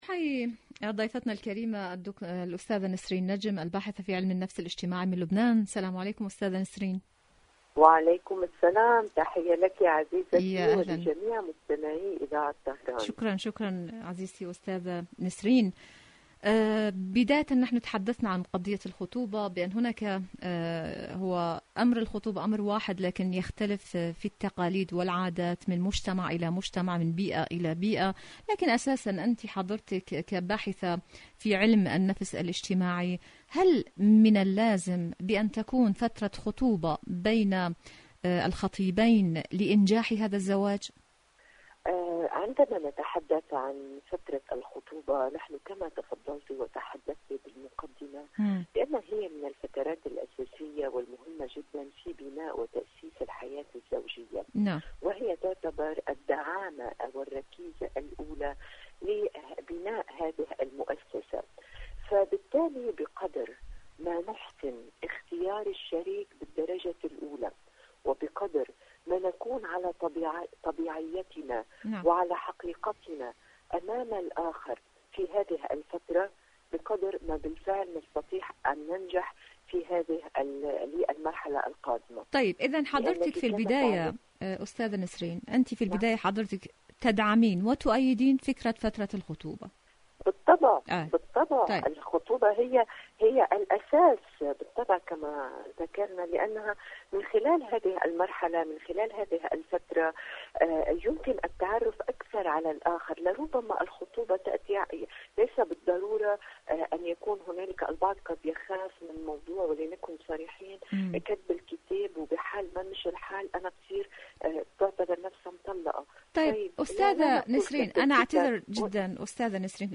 مقابلات برامج إذاعة طهران العربية برنامج دنيا الشباب الشباب مقابلات إذاعية الخطوبة فترة الخطوبة الشباب وفترة الخطوبة شاركوا هذا الخبر مع أصدقائكم ذات صلة آليات إيران للتعامل مع الوكالة الدولية للطاقة الذرية..